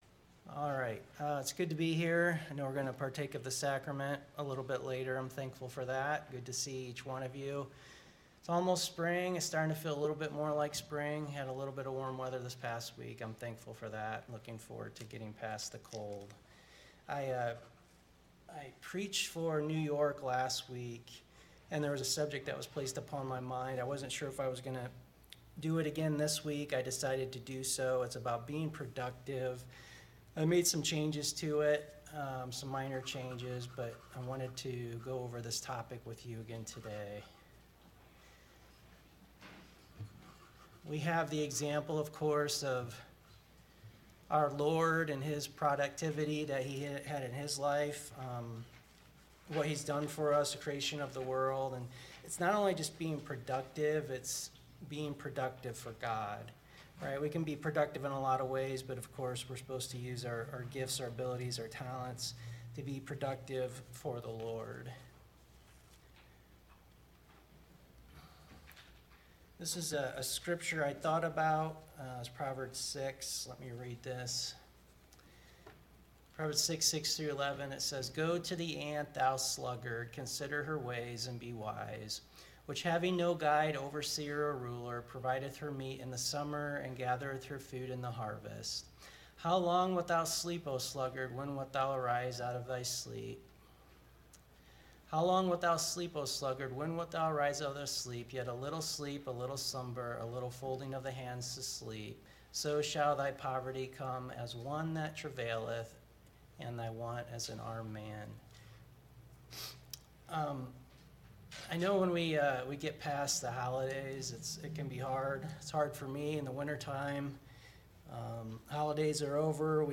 Location: Bradley Congregation